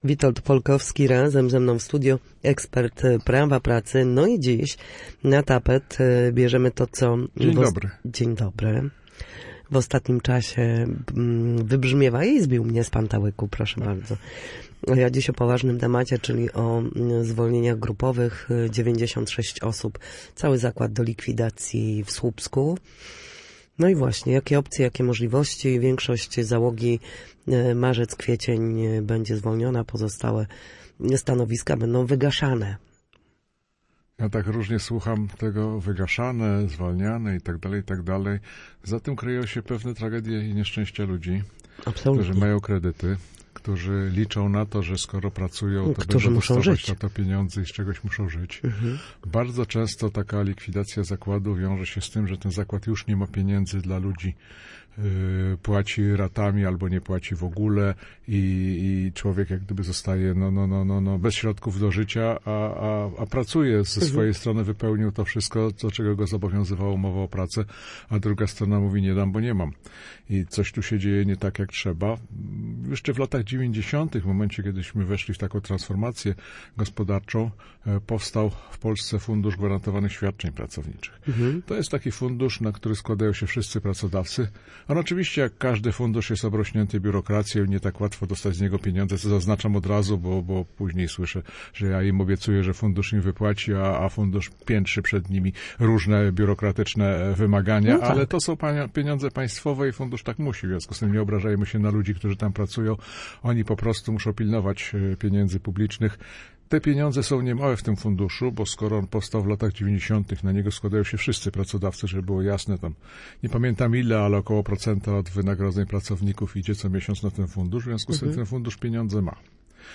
Między innymi na ten temat rozmawialiśmy w Studiu Słupsk.